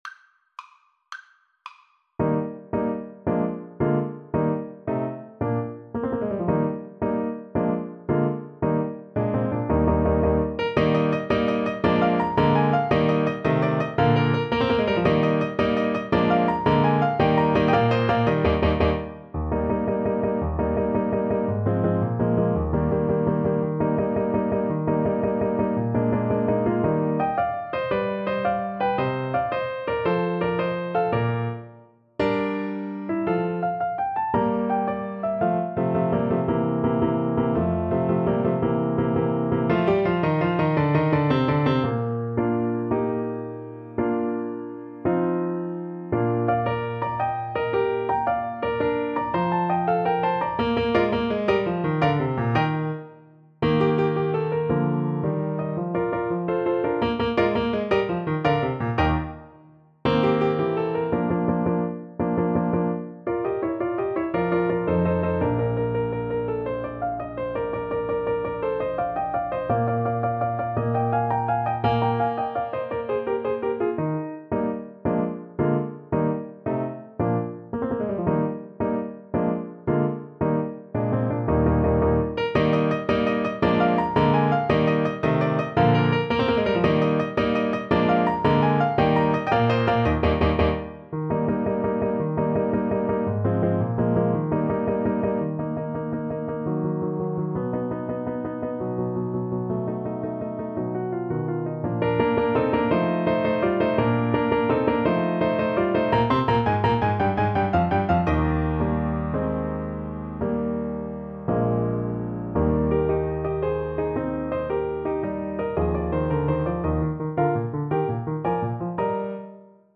Play (or use space bar on your keyboard) Pause Music Playalong - Piano Accompaniment Playalong Band Accompaniment not yet available reset tempo print settings full screen
6/8 (View more 6/8 Music)
Eb major (Sounding Pitch) (View more Eb major Music for Tenor Horn )
.=112 Allegro vivace (View more music marked Allegro)